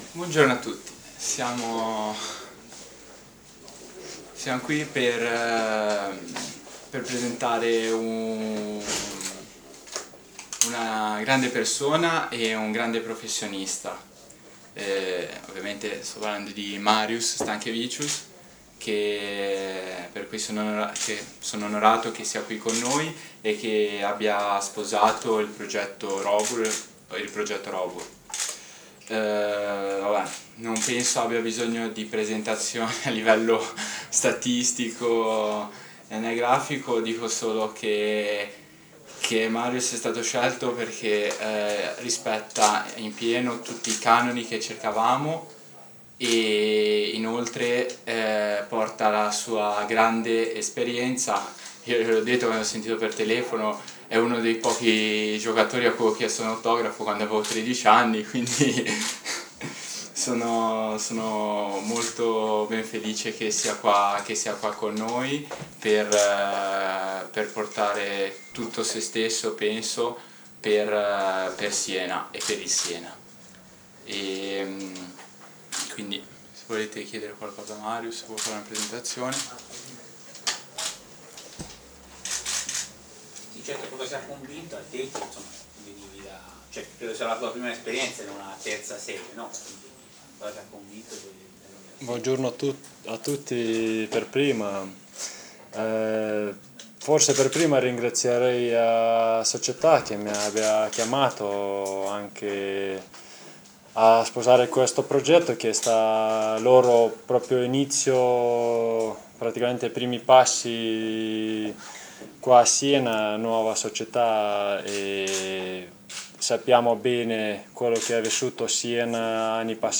Marius Stankevicius si presenta alla stampa - Antenna Radio Esse